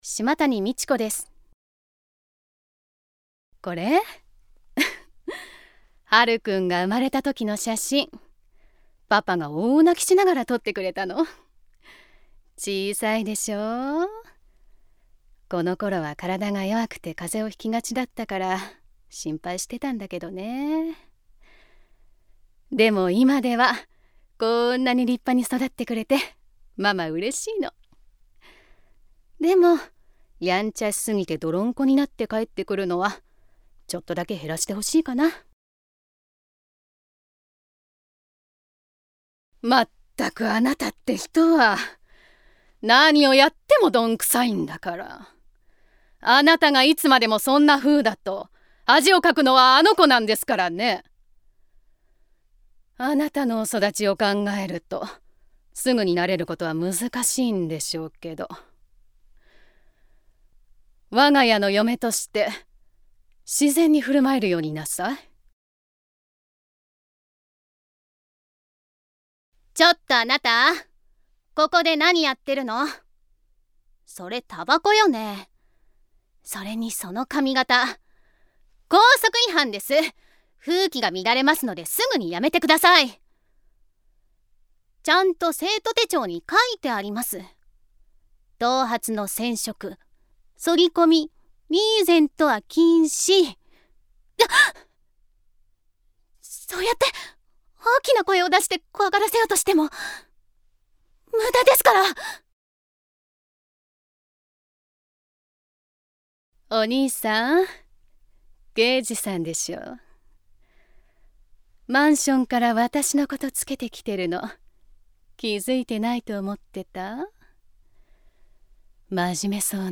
誕生日： 9月15日 血液型： A型 身 長： 157cm 出身地： 岡山県 趣味・特技： 電話対応・着物の着付け・刺繍・お絵描き 資格： 日本漢字能力検定準2級・色彩検定3級・普通自動車免許 方言： 岡山弁
VOICE SAMPLE